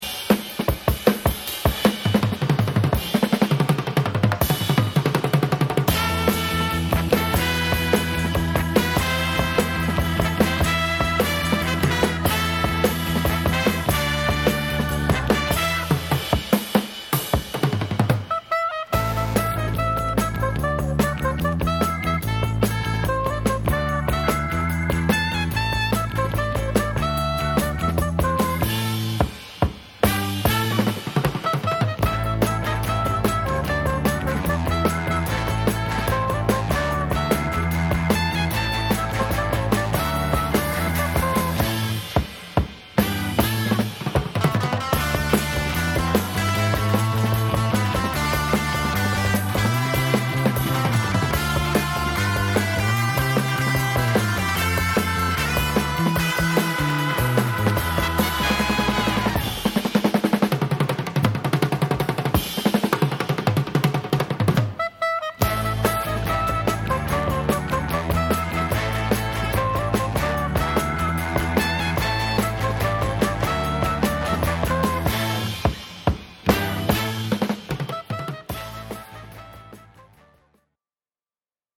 和製レアグルーヴ／ドラムブレイク／インスト集 試聴
インスト・ヴァージョン